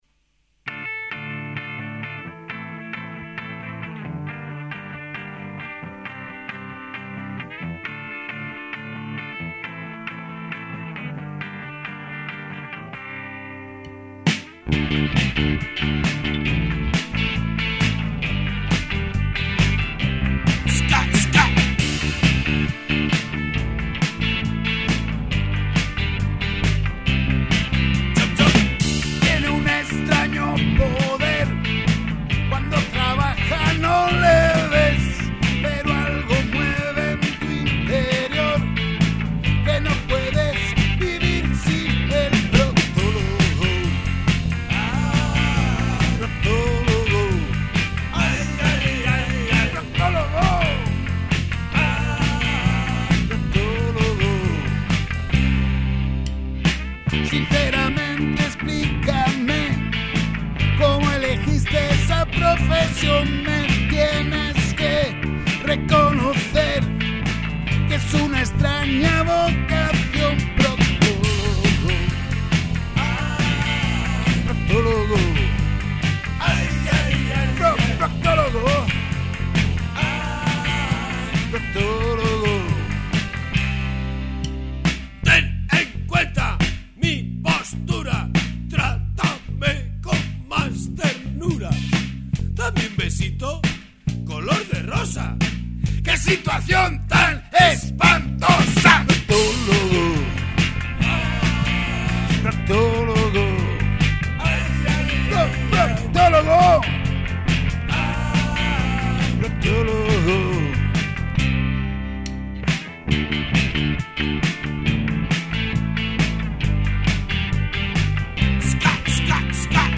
Voz y guitarra eléctrica
Guitarra rítmica, coros y rapeado
Bajo y coros
Batería